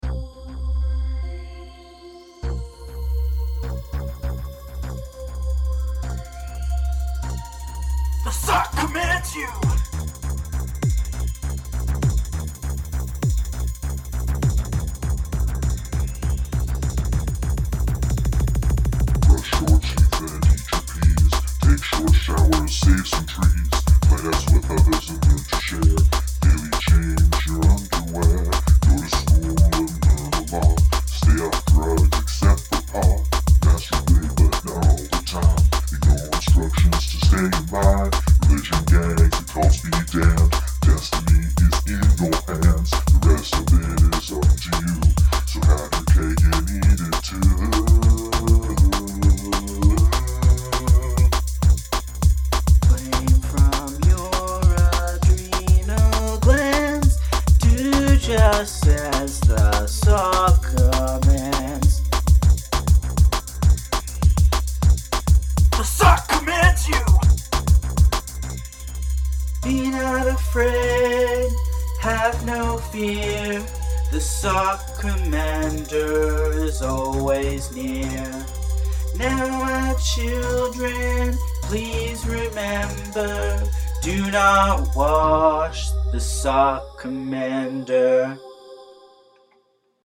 The vocals are a bit poor, I want to redo them cleaner.
As far as the song goes, I like it except for the small ticking noise. The increase in pitch every 1st beat gets to me.
But the background music is a little samey, I like the vocals, lyrics and distortions just the music is lacking a little.